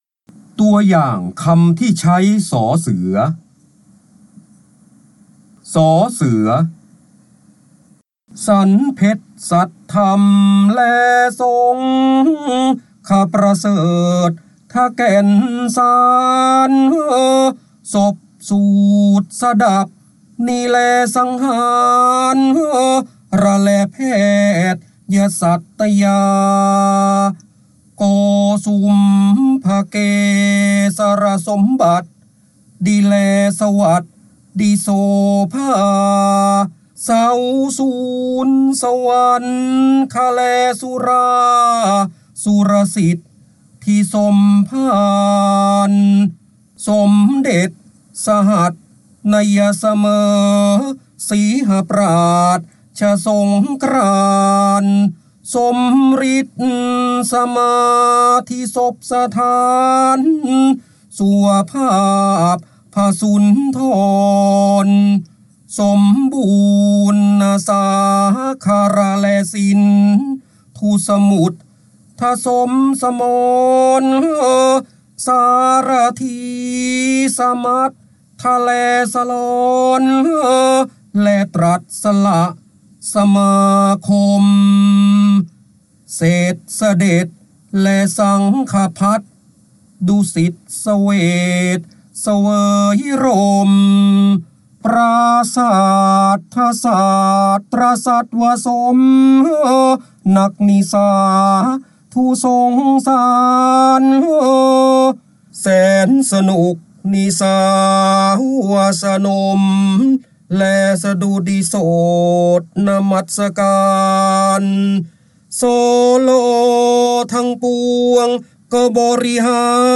เสียงบรรยายจากหนังสือ จินดามณี (พระโหราธิบดี) ตัวอย่างคำที่ใช้ ส
คำสำคัญ : พระเจ้าบรมโกศ, ร้อยกรอง, พระโหราธิบดี, ร้อยแก้ว, การอ่านออกเสียง, จินดามณี
ลักษณะของสื่อ :   คลิปการเรียนรู้, คลิปเสียง